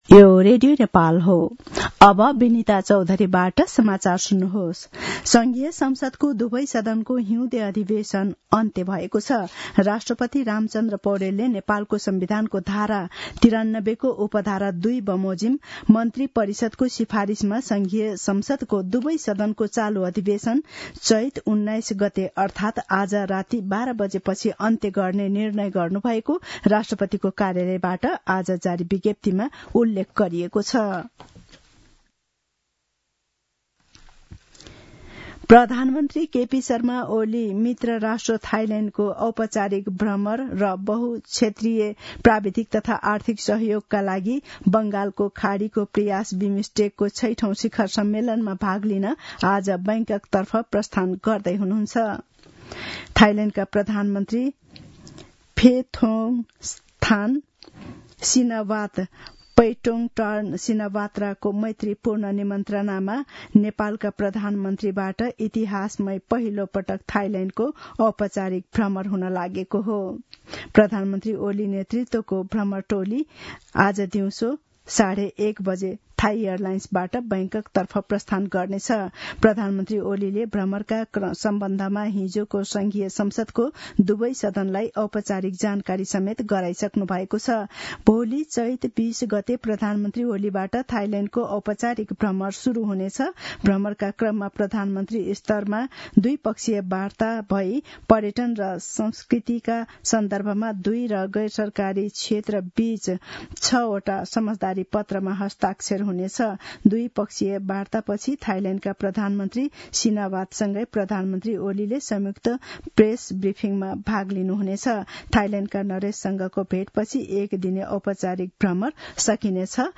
दिउँसो १ बजेको नेपाली समाचार : १९ चैत , २०८१
1-pm-news-.mp3